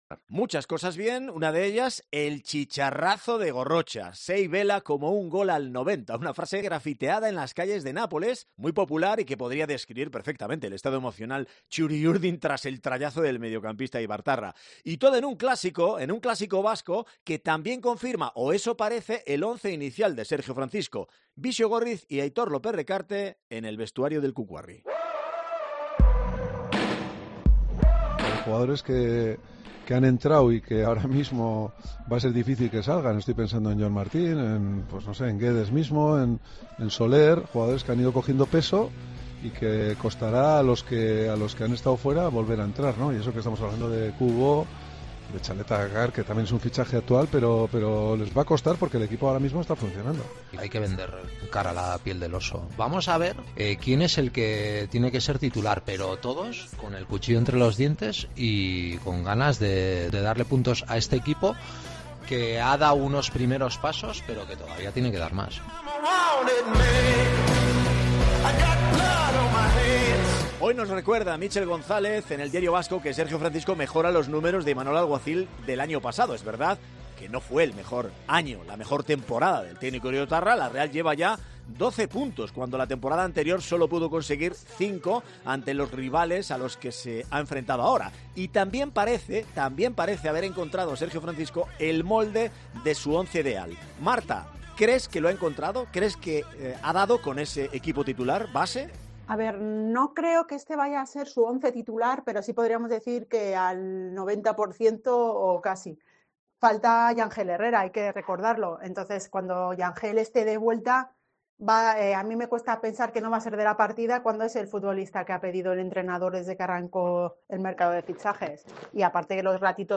Debate sobre el once ideal de la Real Sociedad